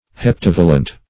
Search Result for " heptavalent" : The Collaborative International Dictionary of English v.0.48: Heptavalent \Hep*tav"a*lent\, a. [Hepta- + L. valens, p. pr.